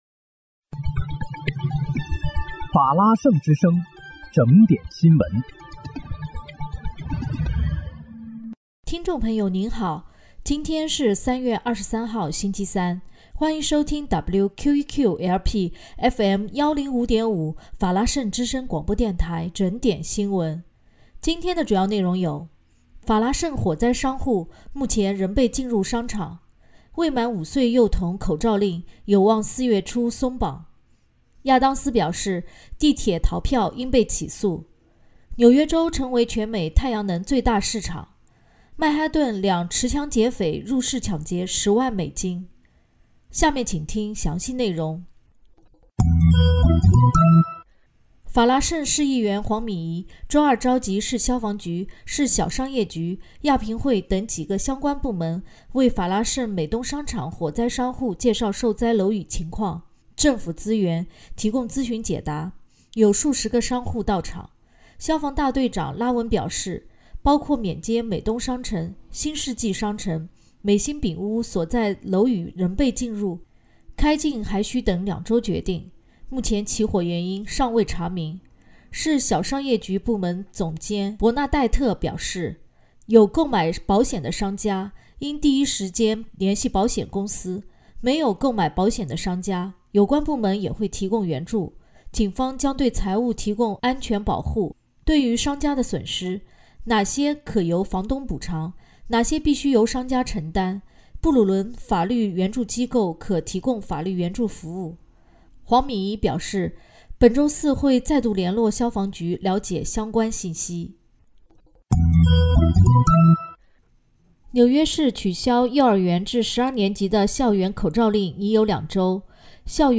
3月23日（星期三）纽约整点新闻